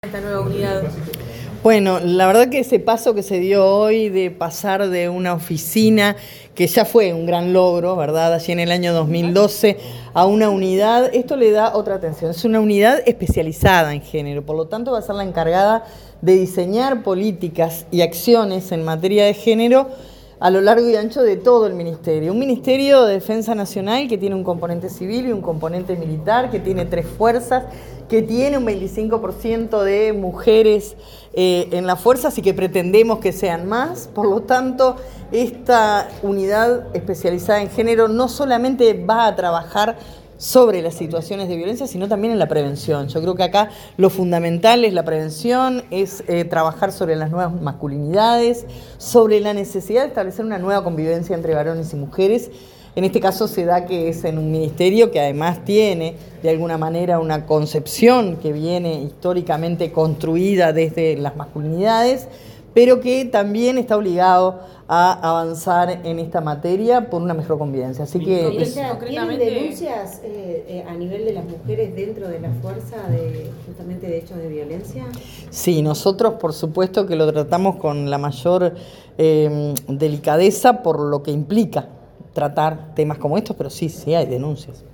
Declaraciones de la ministra de Defensa, Sandra Lazo
Declaraciones de la ministra de Defensa, Sandra Lazo 18/11/2025 Compartir Facebook X Copiar enlace WhatsApp LinkedIn Tras la presentación de la Unidad Especializada en Género del Ministerio de Defensa Nacional, la titular de la cartera, Sandra Lazo, diálogo con los medios de prensa.